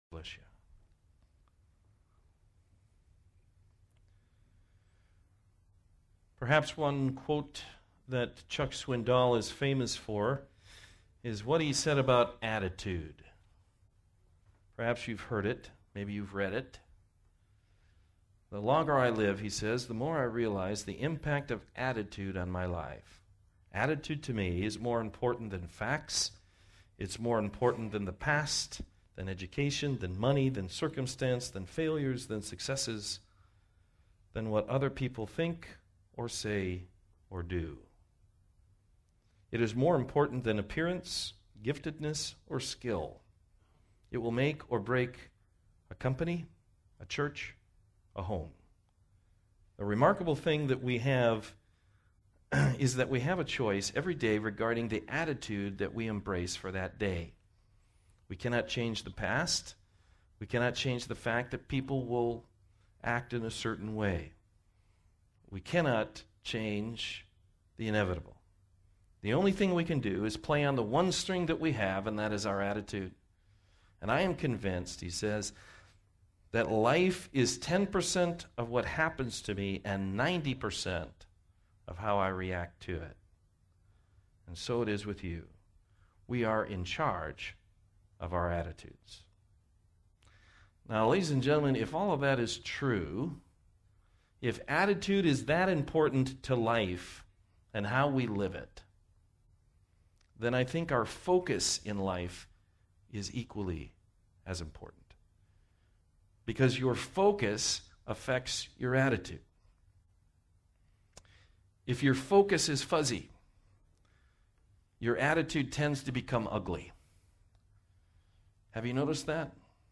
Isaiah 40:25-27 Service Type: Morning Service The God of Heaven is a shepherd who never loses track of his sheep!